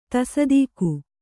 ♪ tasadīku